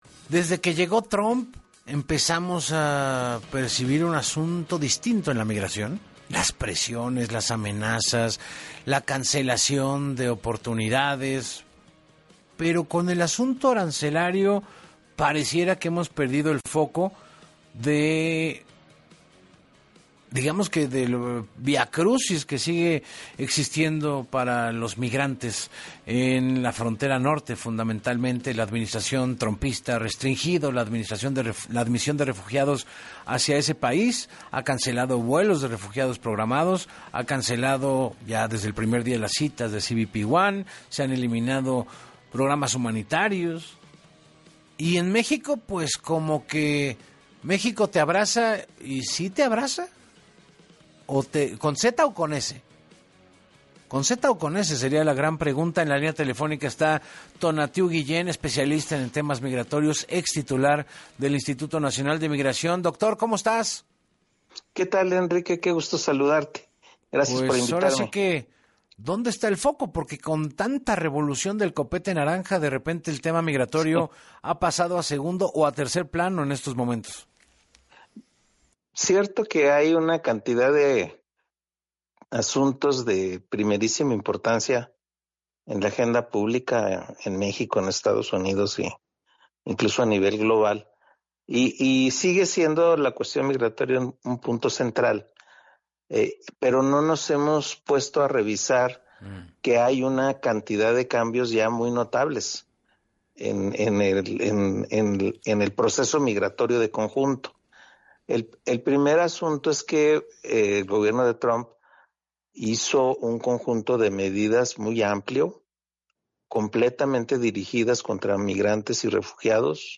En Así Las Cosas con Enrique Hernández Alcázar, el ex comisionado del INM indicó que esto se tradujo en la declaración de emergencia nacional y militarización de la frontera.